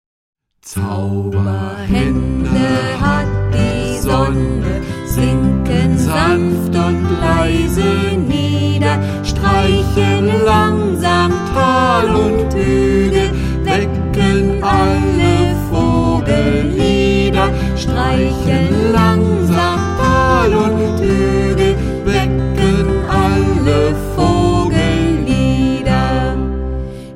Beruhigende und bewegende Lieder